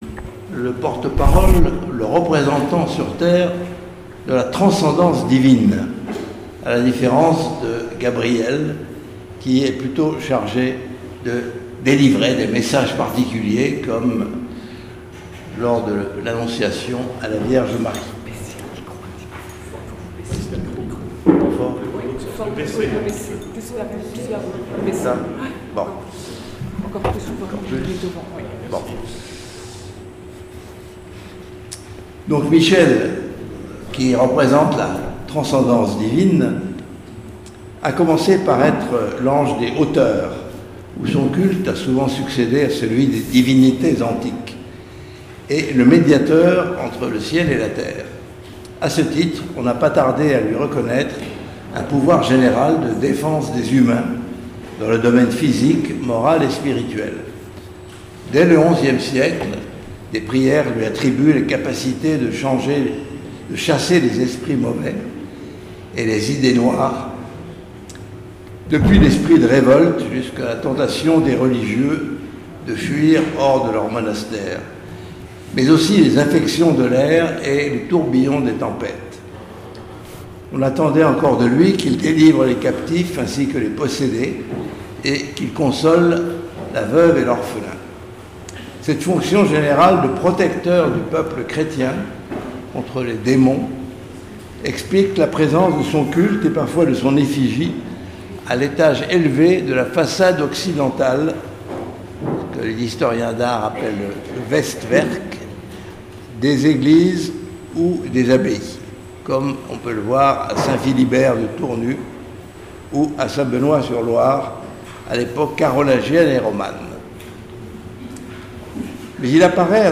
André Vauchez - Inauguration Bât Culturel — Pélerins Mont Saint Michel